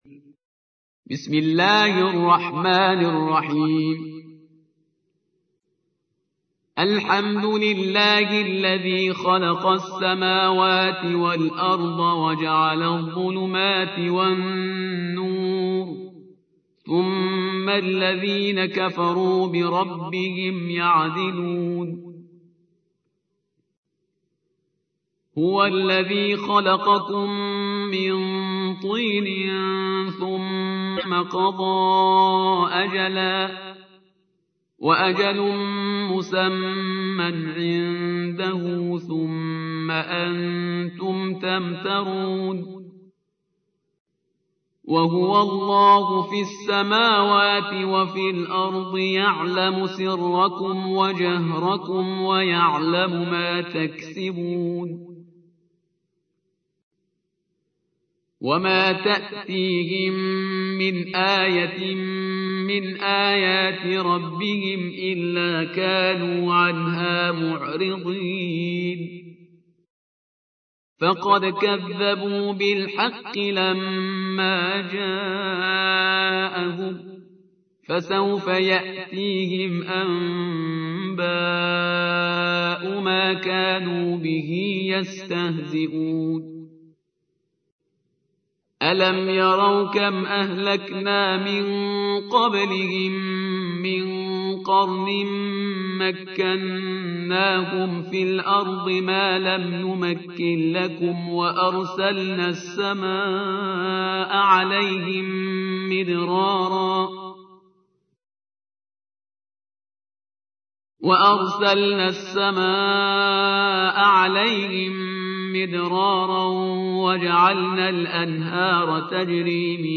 القرآن الكريم